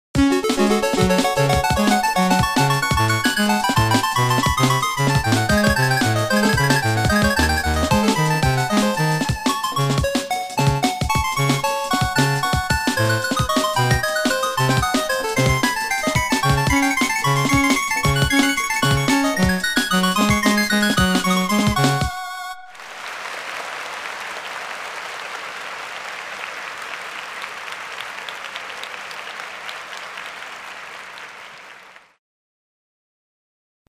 Heavy metal hip hop tribute